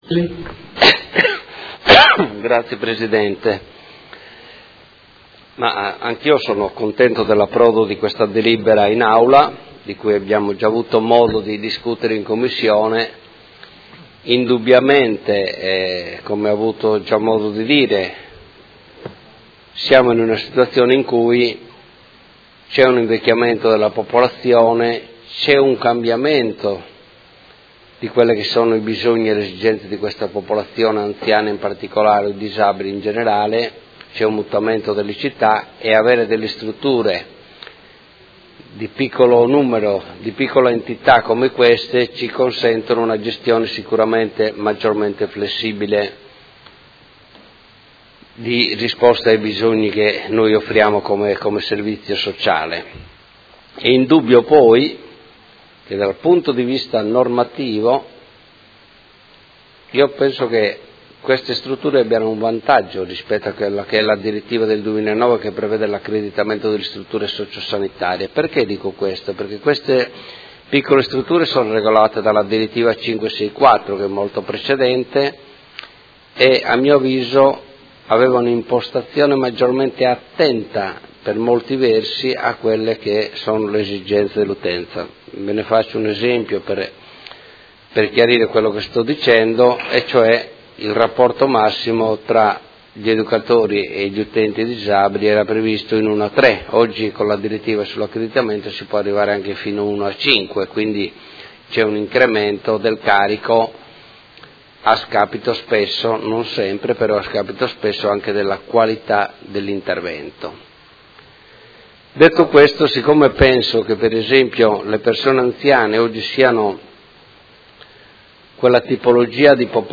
Seduta del 15/03/2018. Dibattito su proposta di deliberazione: Approvazione del Regolamento per la Disciplina delle Case famiglia per anziani e disabili adulti